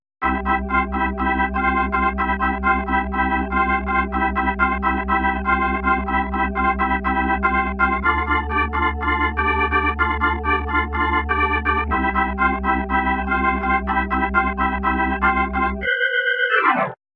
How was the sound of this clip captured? > short demo, sounds decent (modulo crappy keyboard setup / keyboard That actually sounds pretty decent!